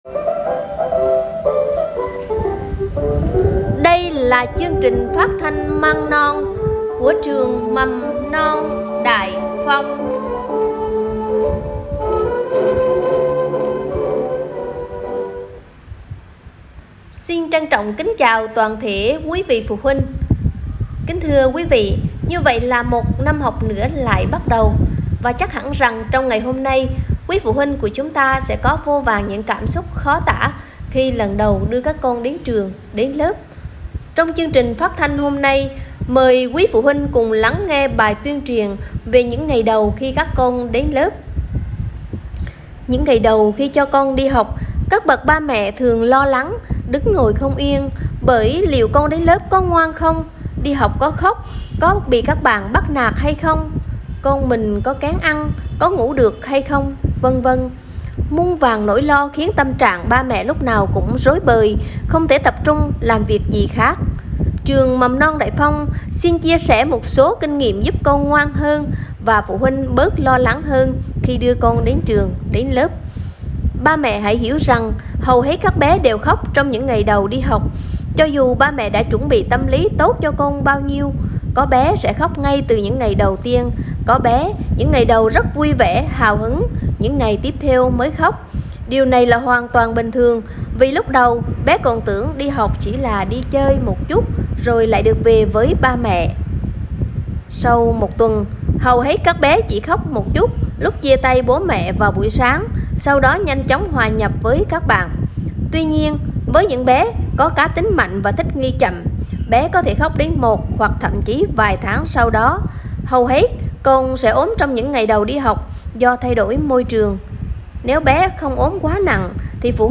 Chương trình phát thanh tuyên truyền với phụ huynh giúp trẻ thích nghi với trường Mầm non